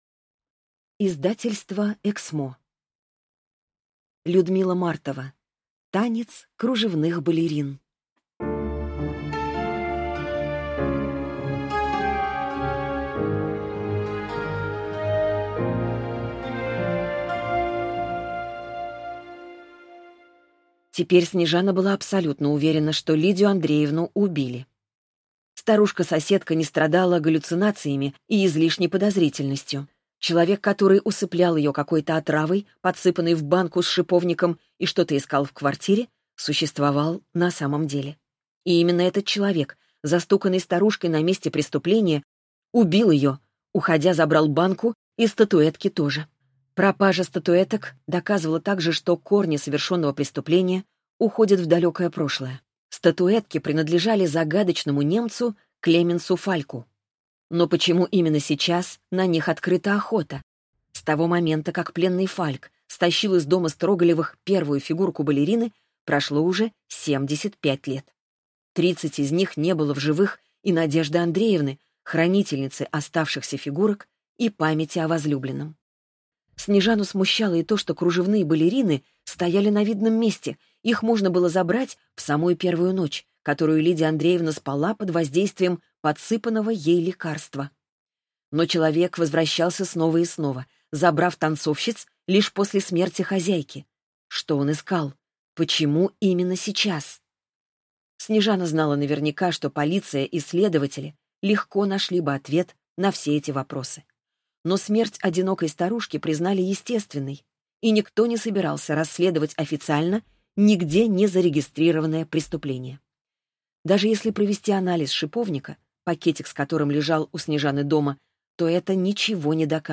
Слушать аудиокнигу Одарённый.